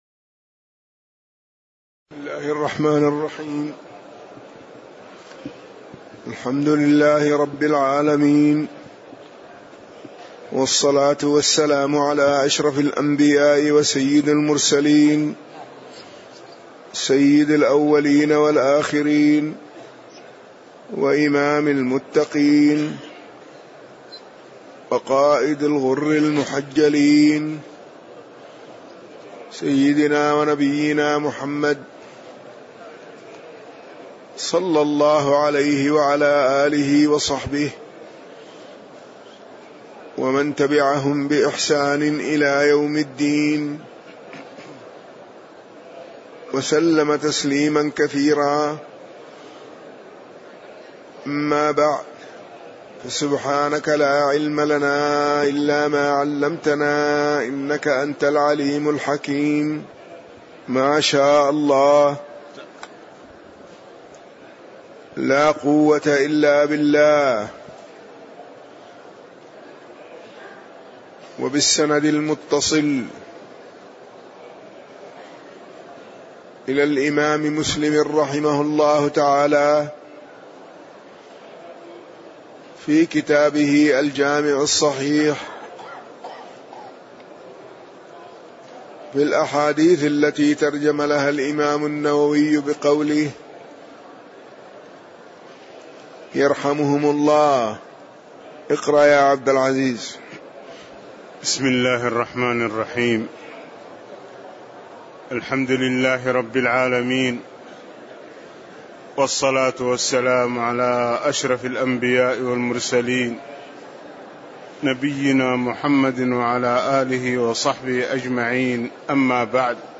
تاريخ النشر ١٩ ذو الحجة ١٤٣٧ هـ المكان: المسجد النبوي الشيخ